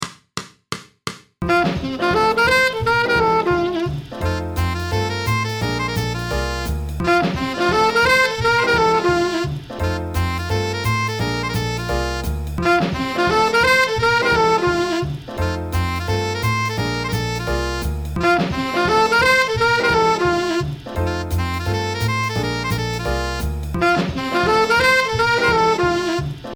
2. イーブン8分で打ち込み
4. 3連で打ち込み
6. 16分で打ち込み
8. 5連で打ち込み
の順番で演奏されている音源を作ってみました。
この二小節だけ聞くと3連の方がハマってるようにも聴こえますが、単独で聞くと、端的に5連が一番ダサくないんですよね。